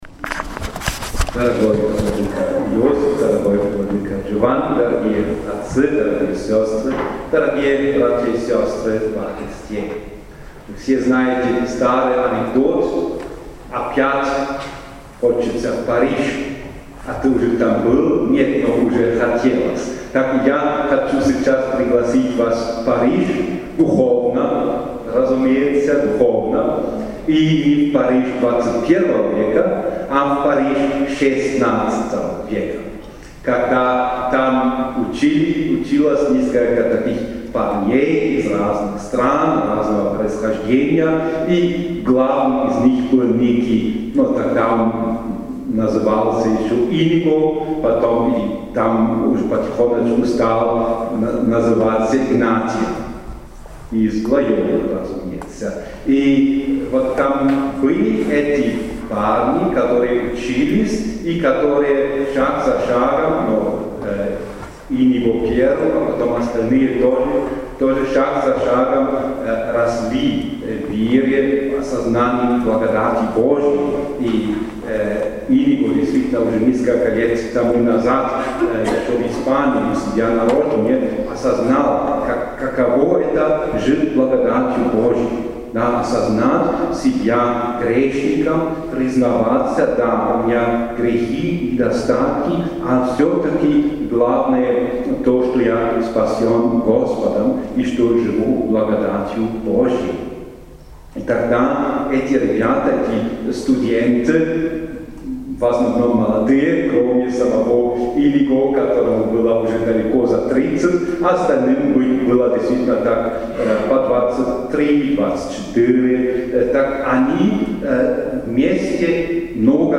Юбилейные торжества, связанные с этой памятной датой, проходили весь день на территории Кафедрального собора и Епархиального центра им. епископа-исповедника Александра Хиры.
propoved-episkopa-SHtefana-Lipke.mp3